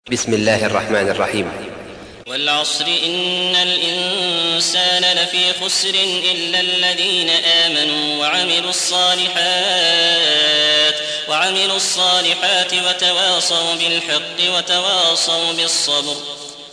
سورة العصر / القارئ